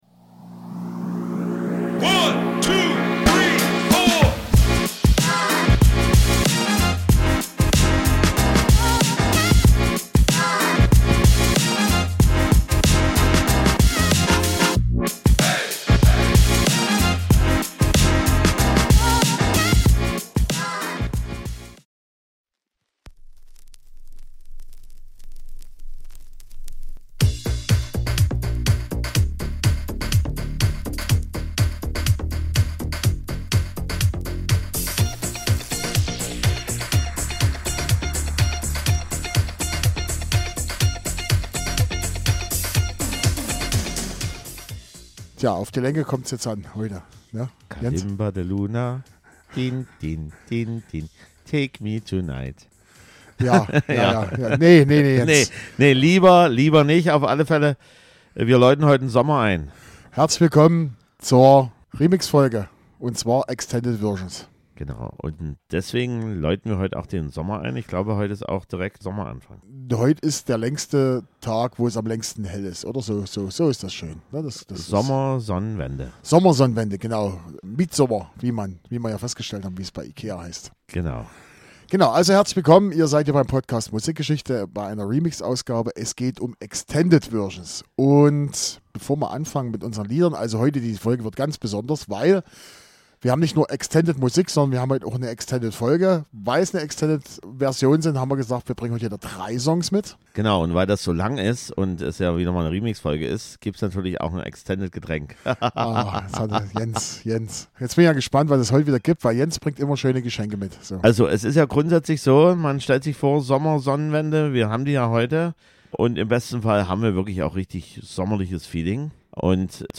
Und da heute der längste Tag des Jahres ist, hat diese Ausgabe Überlänge und sogar 6 Songs dabei.